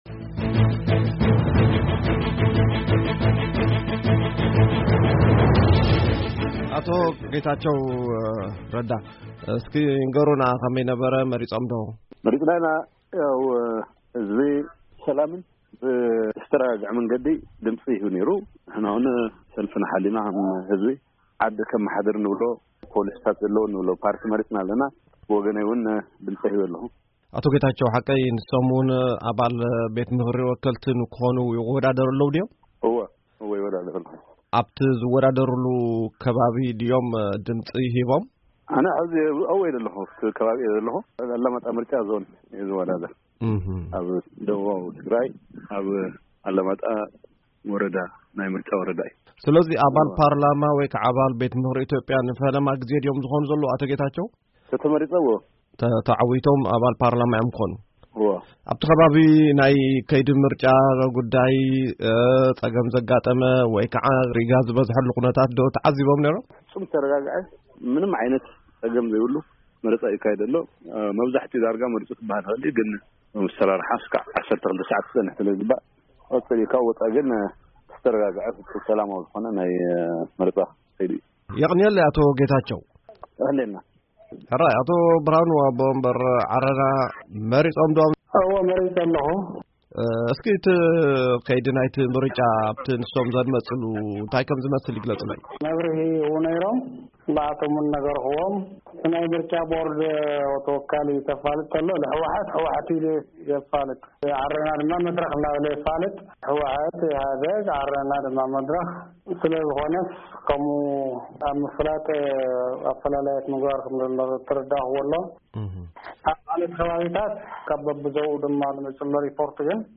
ዝተዋደደ ሪፖርት ምርጫ ኢትዮጵያ